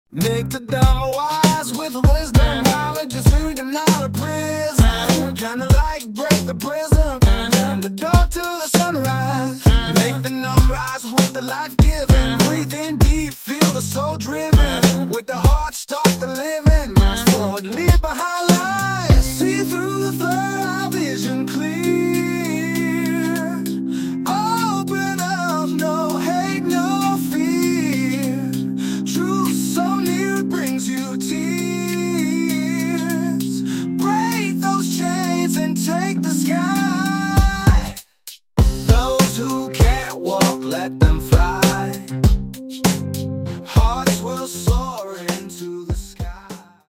An incredible R&B song, creative and inspiring.